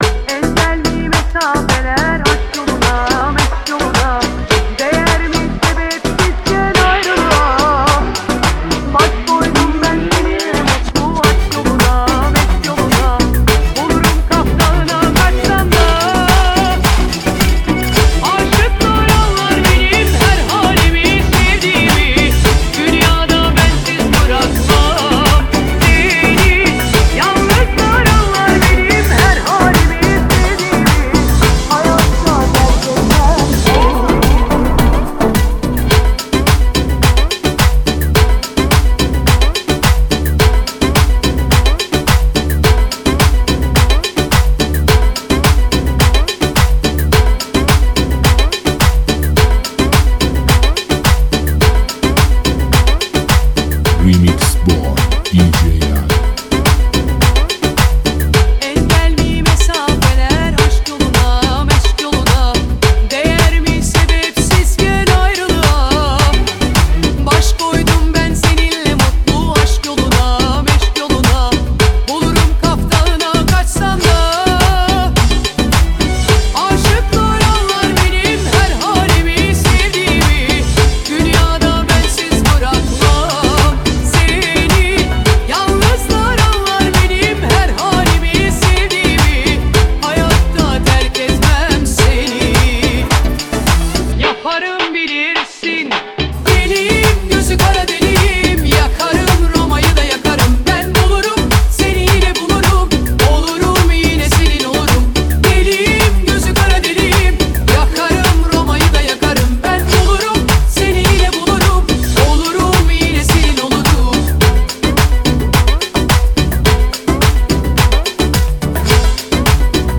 موسیقی شاد و پرانرژی برای لحظاتی پر از احساس و شادی.